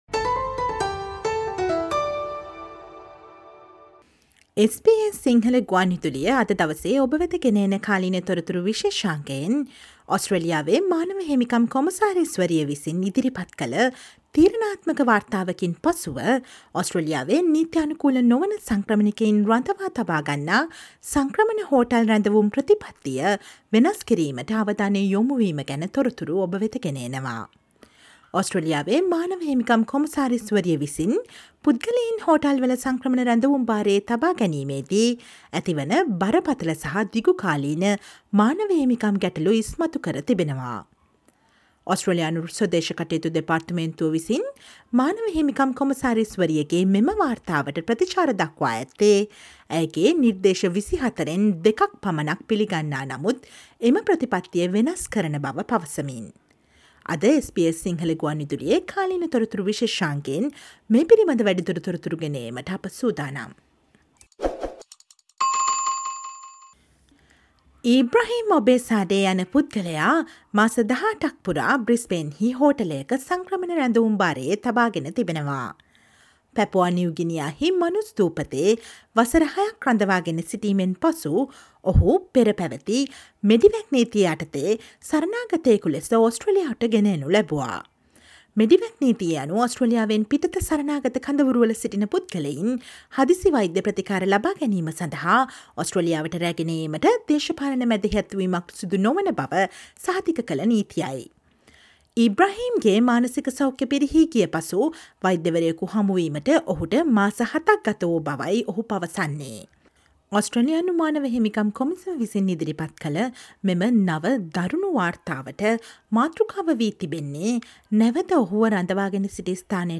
Listen to the SBS Sinhala radio current affair feature on the Australia’s Human Rights Commissioner revealing human rights issues in immigration hotel detention and the response of The Department of Home affairs taking steps to change the policy.